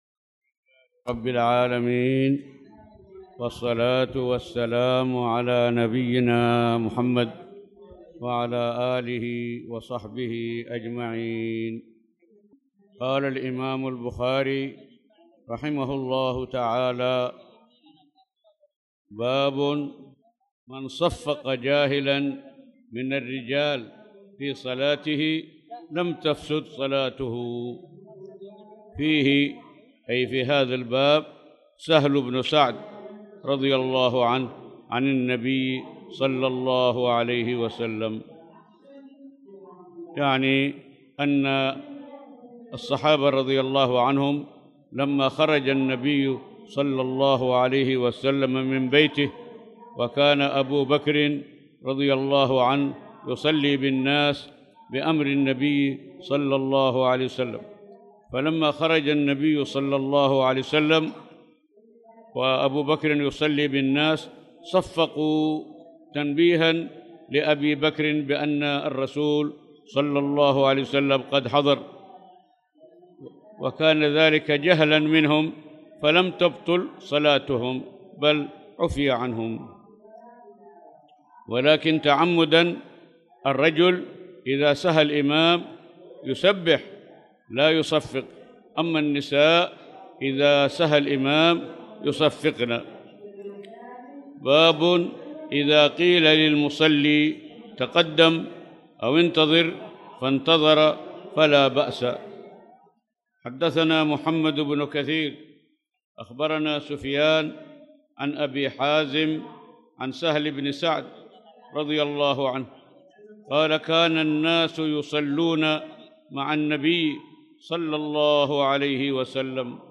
تاريخ النشر ٧ ذو القعدة ١٤٣٧ هـ المكان: المسجد الحرام الشيخ